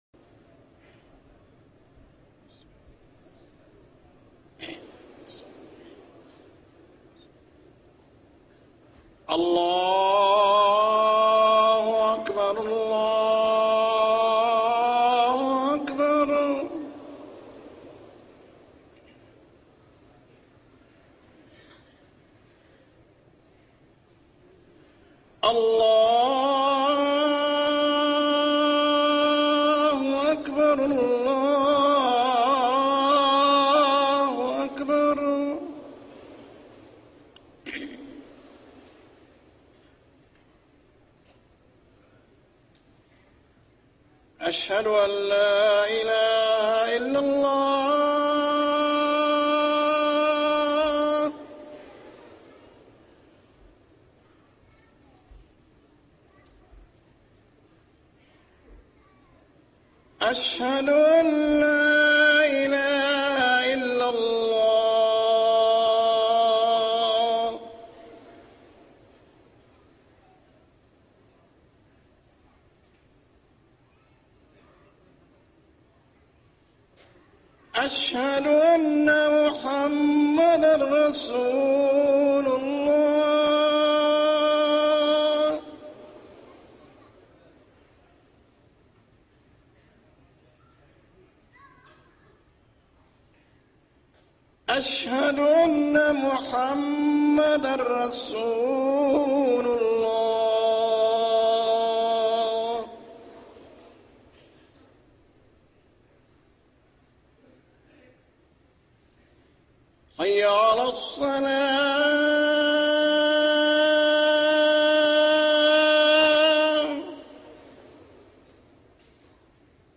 أذان العشاء
المكان: المسجد النبوي